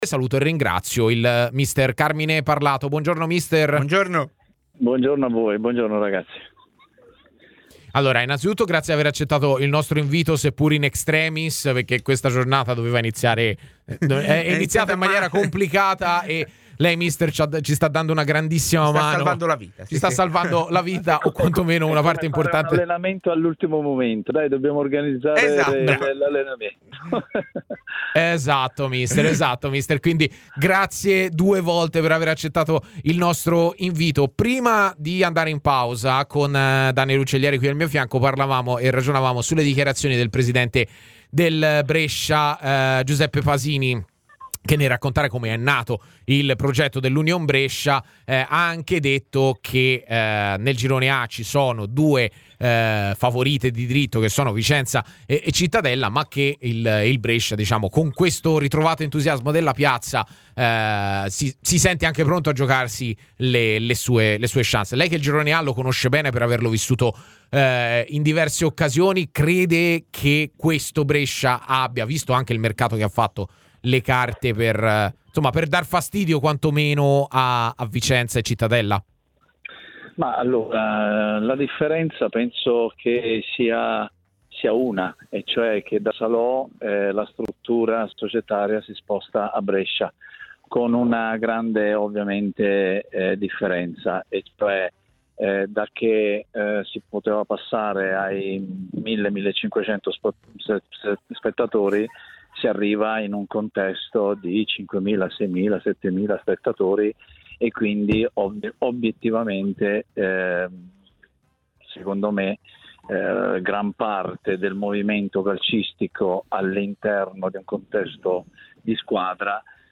trasmissione in onda su TMW Radio e su Il 61, canale 61 del digitale terrestre.